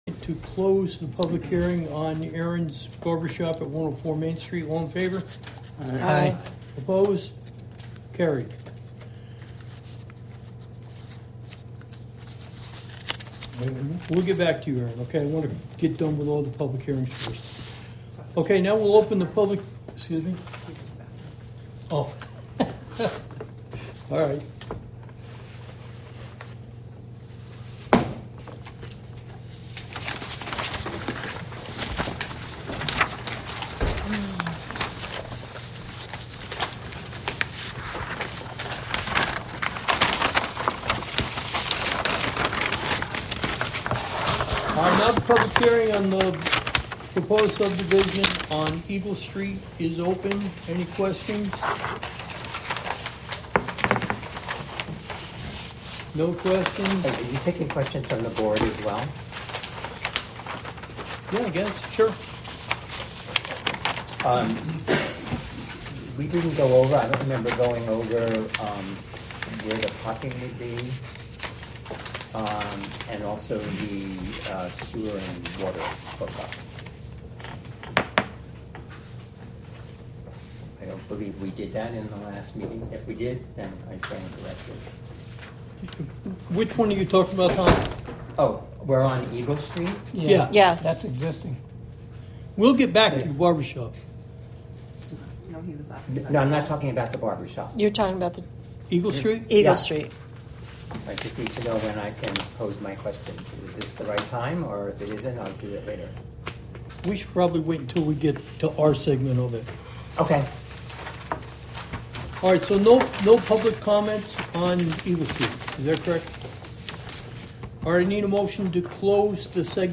Live from the Village of Philmont: Philmont Planning Board Meeting 9-16-25 (Audio)